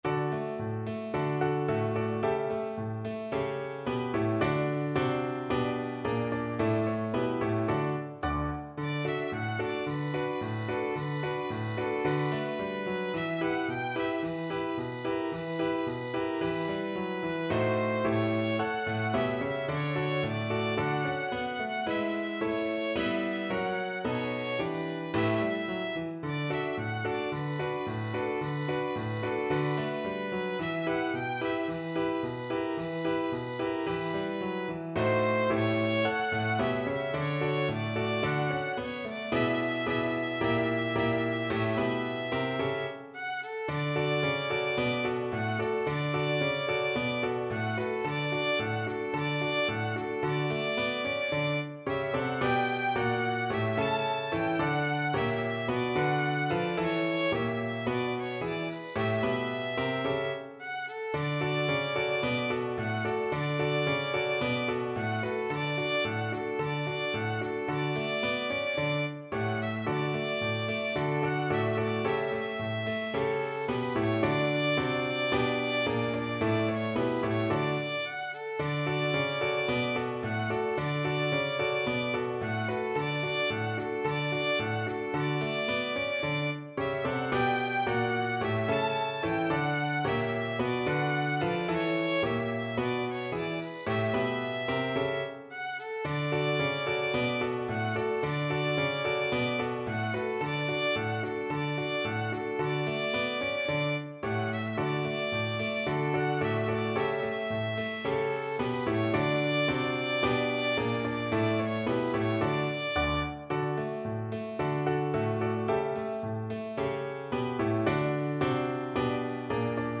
Violin version
2/4 (View more 2/4 Music)
Moderato allegro =110
Classical (View more Classical Violin Music)